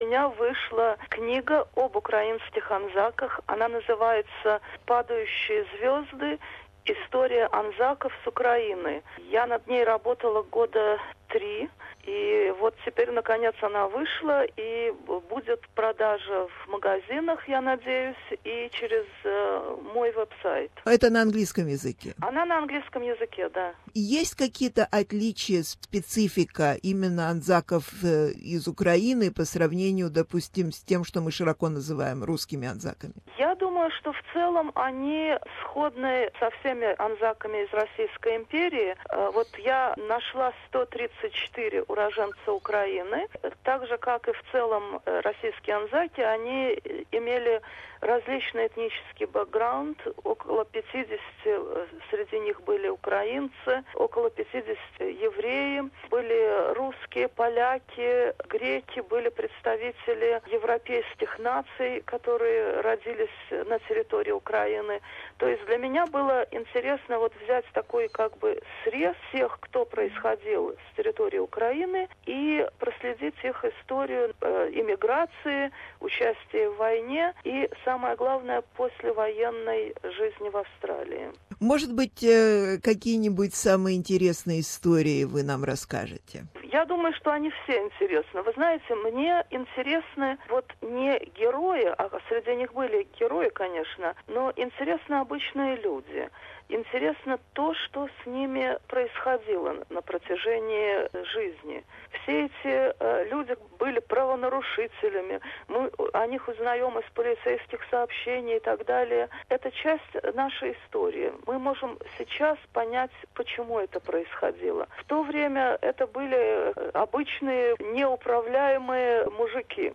беседовать с историком, писателем